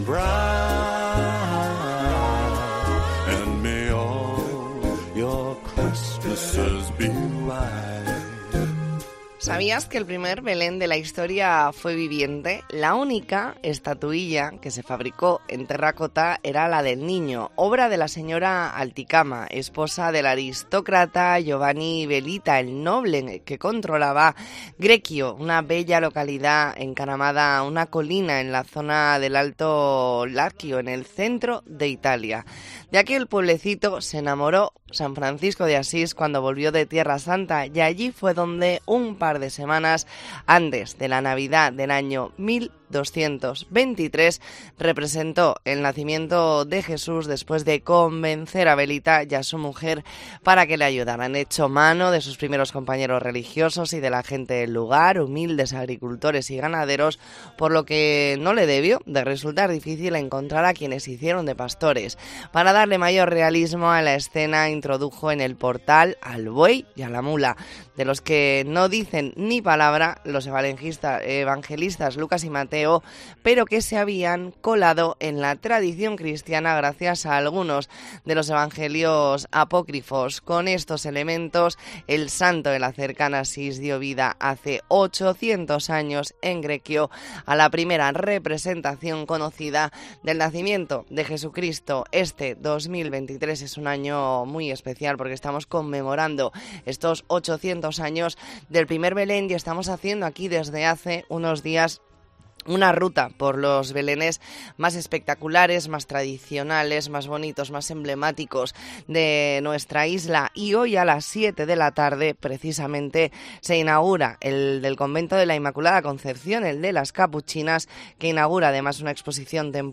Entrevista en La Mañana en COPE Más Mallorca, viernes 15 de diciembre de 2023.